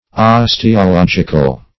osteological - definition of osteological - synonyms, pronunciation, spelling from Free Dictionary
Search Result for " osteological" : The Collaborative International Dictionary of English v.0.48: Osteologic \Os`te*o*log"ic\, Osteological \Os`te*o*log"ic*al\, a. [Cf. F. ost['e]ologique.]